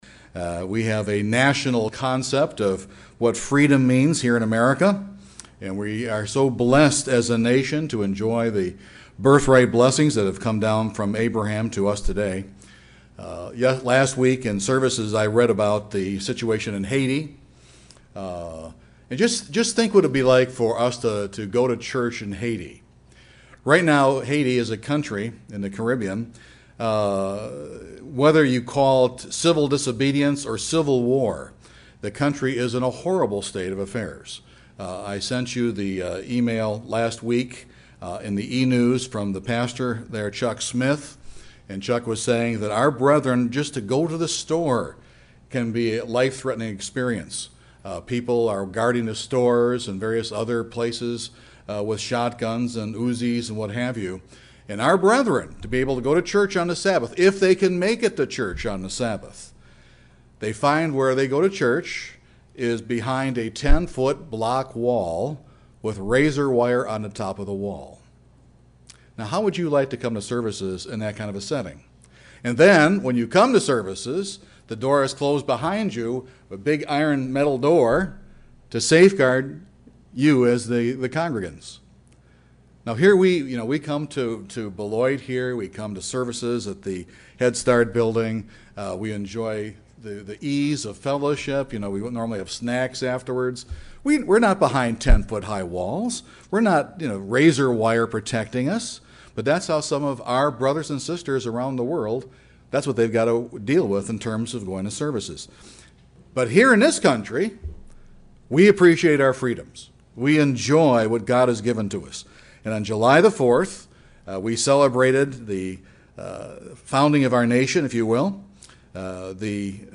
The 4th of July celebrates America's independence and her freedoms, But let's not forget the freedoms God gives us through his law. This sermon will examine a number of the Ten Commandments and document the freedoms each offers us.